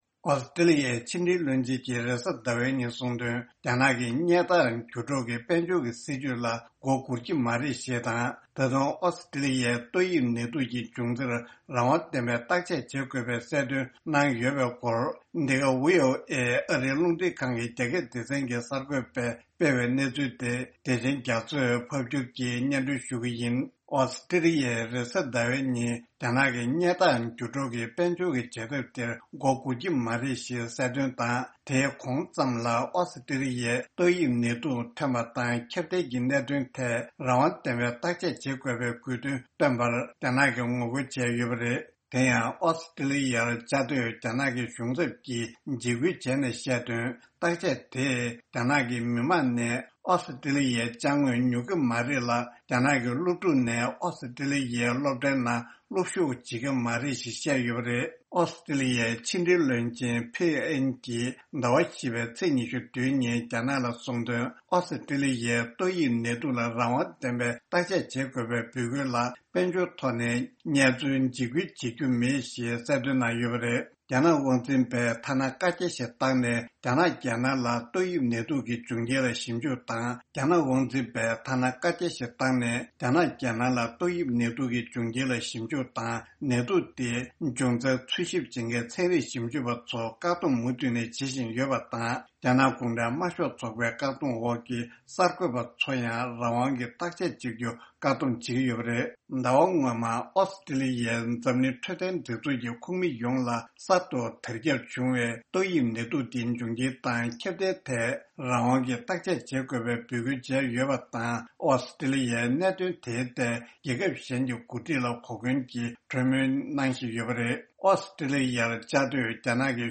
ཕབ་སྒྱུར་གྱིས་སྙན་སྒྲོན་ཞུ་ཡི་རེད།།